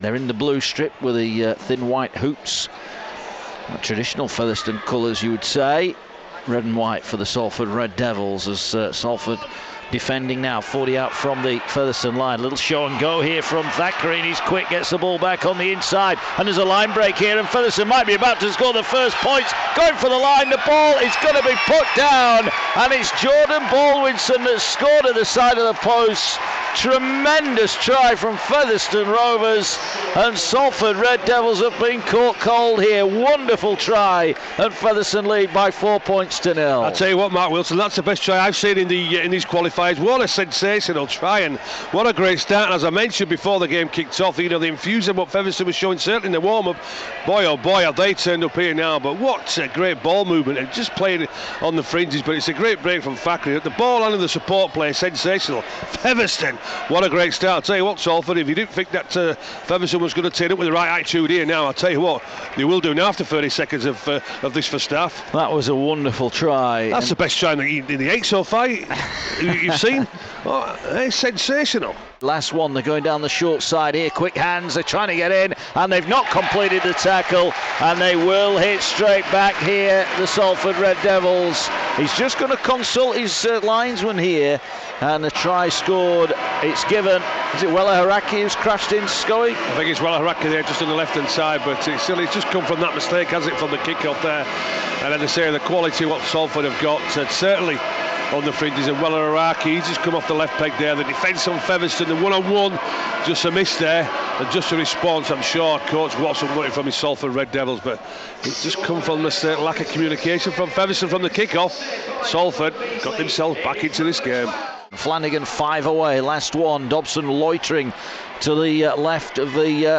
It was an important win for Salford, as they ran out 70-16 winners over Featherstone Rovers. Commentary and Analysis